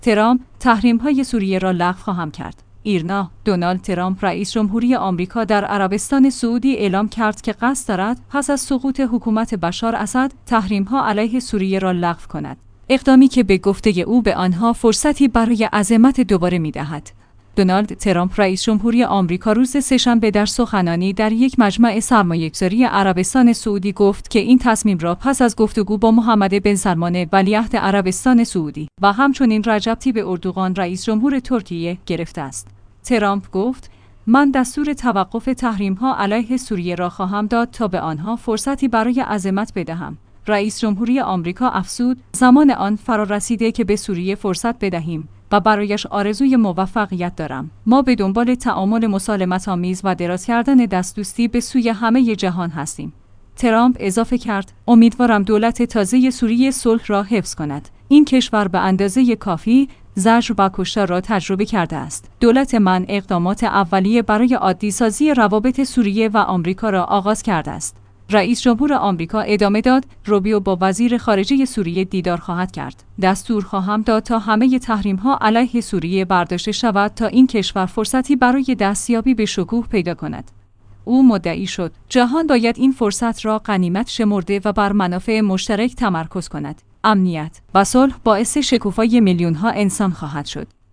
دونالد ترامپ رئیس جمهوری آمریکا روز سه شنبه در سخنانی در یک مجمع سرمایه‌گذاری عربستان سعودی گفت که این